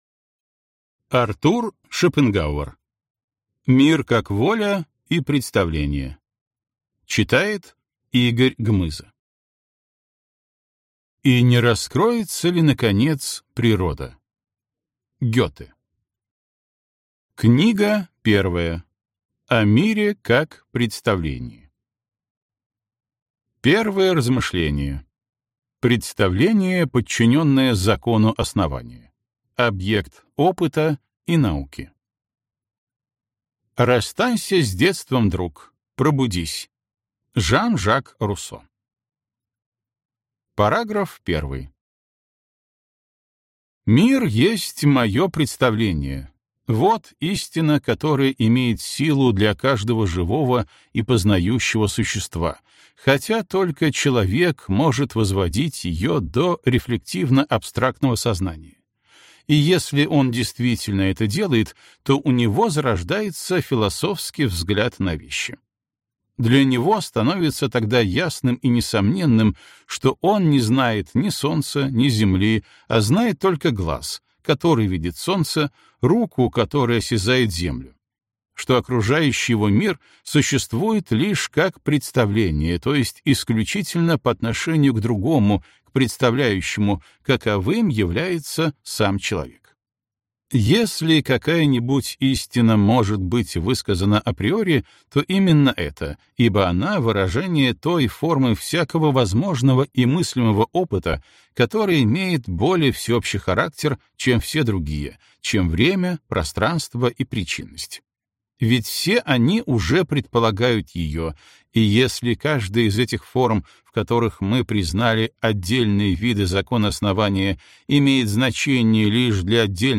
Аудиокнига Мир как воля и представление | Библиотека аудиокниг